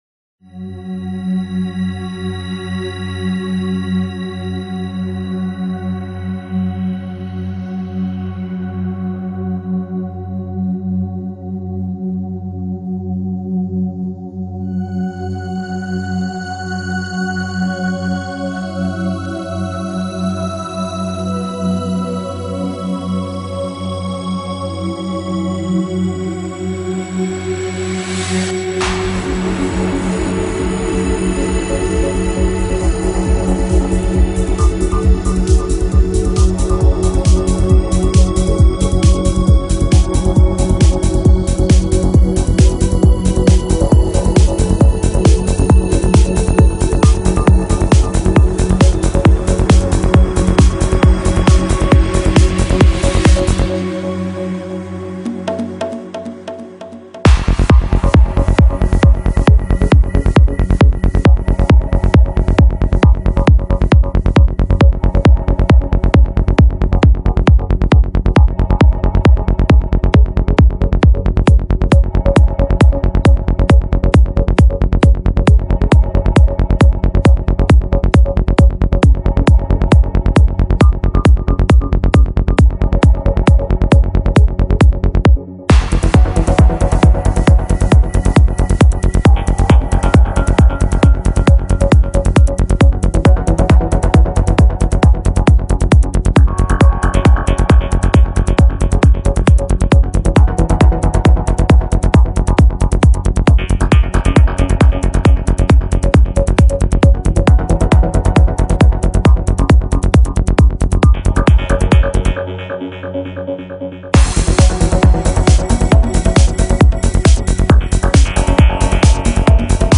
trance psicodélico
singular sonido atmosférico característico